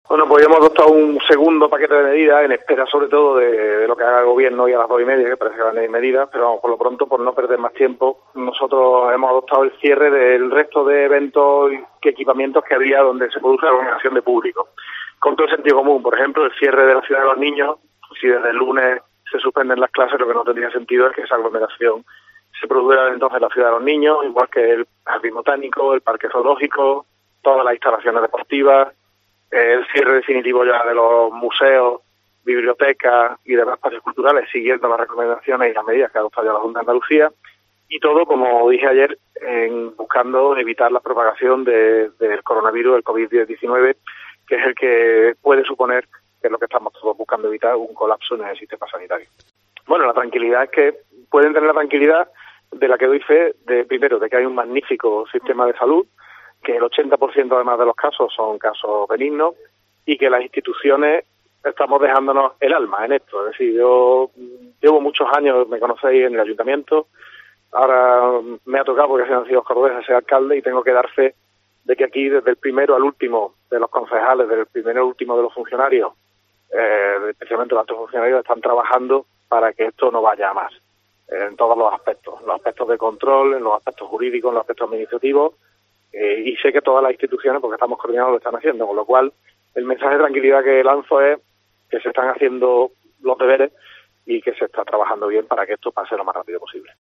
Escucha al alcalde de Córdoba José María Bellido